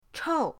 chou4.mp3